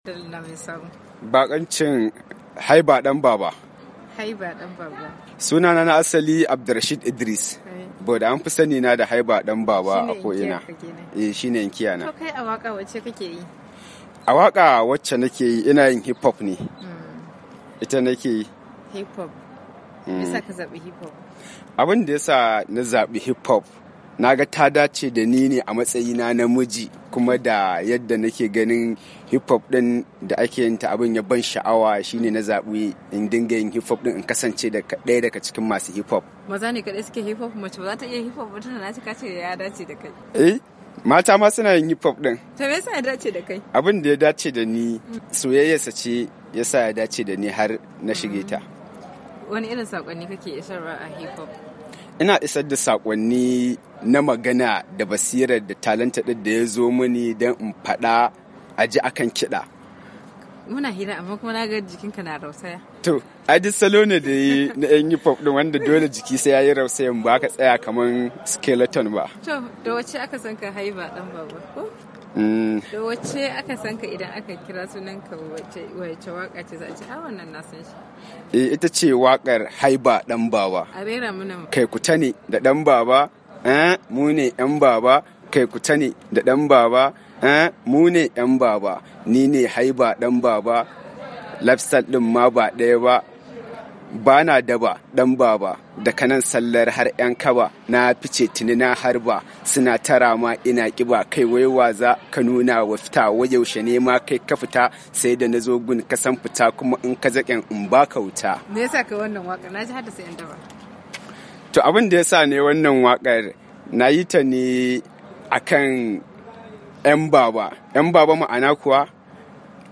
Ya bayyana haka ne a yayin da yake zantawa da wakiliyar DandalinVOA